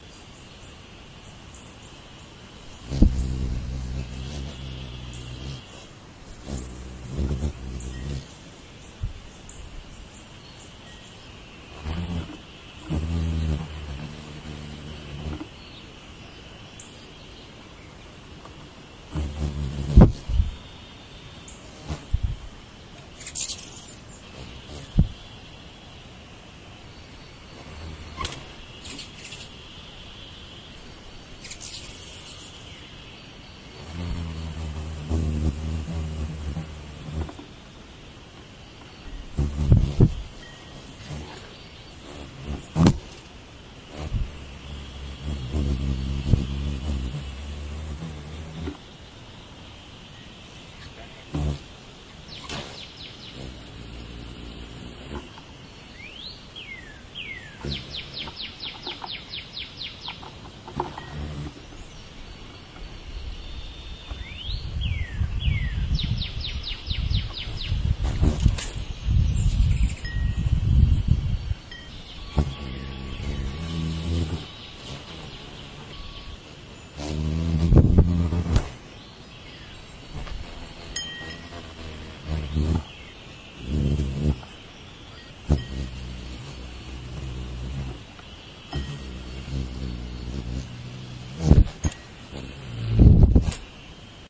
action » Hard Kick
描述：Zero layers added.
标签： Kick Battle Street Fight Fighting Combat impact
声道立体声